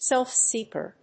アクセントsélf‐séeker
音節self-seek･er発音記号・読み方sèlfsíːkər